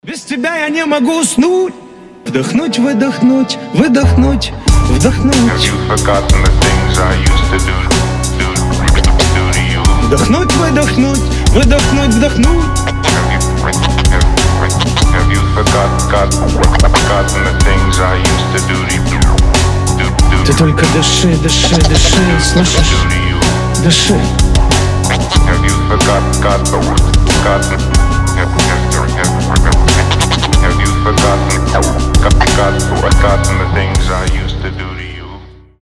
• Качество: 320, Stereo
Хип-хоп
душевные
грустные
атмосферные
красивый мужской голос
Rap-rock
Атмосферная лирика